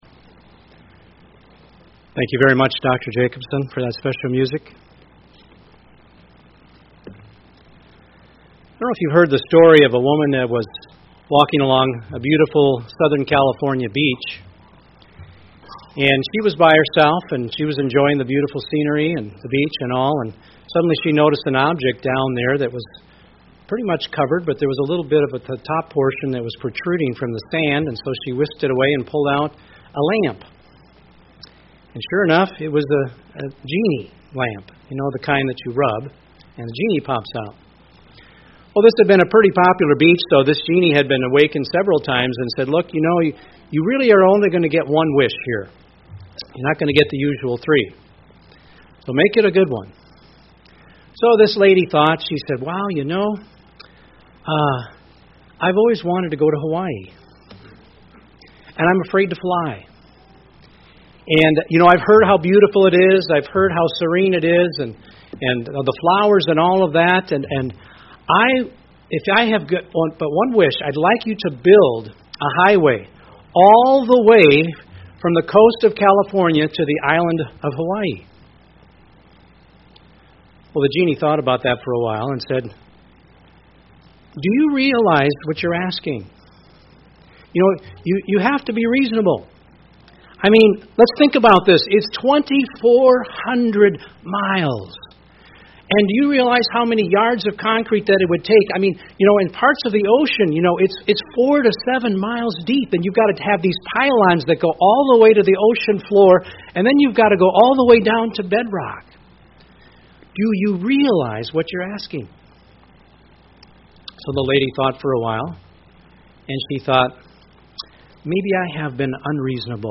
UCG Sermon women Transcript This transcript was generated by AI and may contain errors.